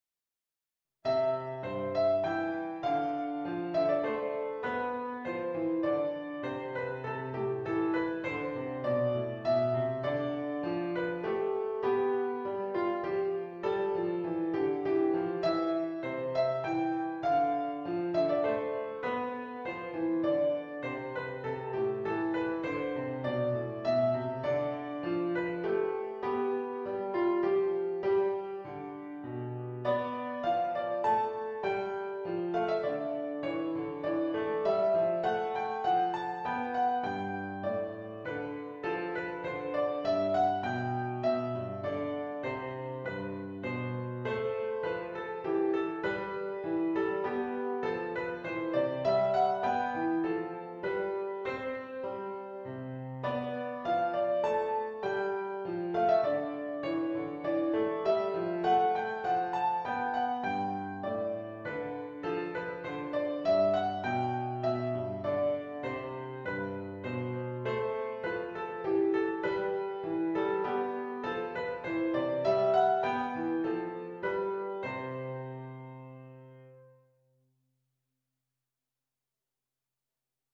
Minuet I - Piano Music, Solo Keyboard - Young Composers Music Forum